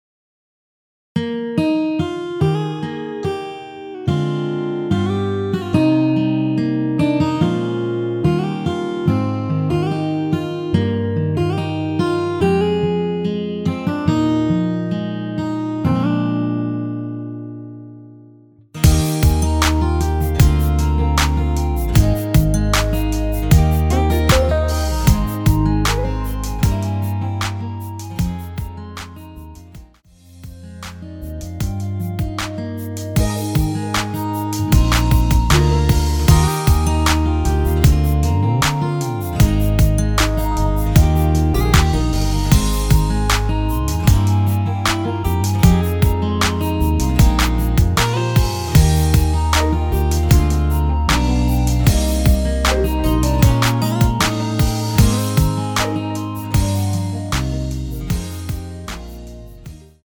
원키(1절+후렴)멜로디 포함된 MR입니다.(미리듣기 확인)
Eb
앞부분30초, 뒷부분30초씩 편집해서 올려 드리고 있습니다.
중간에 음이 끈어지고 다시 나오는 이유는